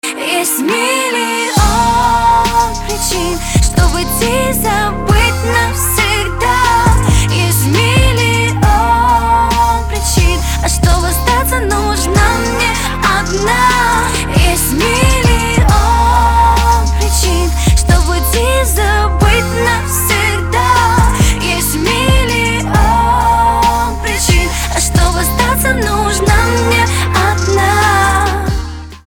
женский вокал
лирика
Хип-хоп
спокойные
медленные
медляк